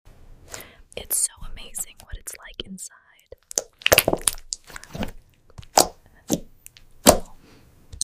STRANGE ASMR | 🌍 sound effects free download